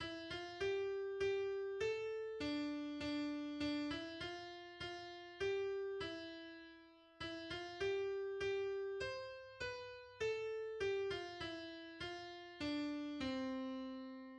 Schneeflöckchen, Weißröckchen (little snowflake, white little skirt) is a German Christmas carol.
Melody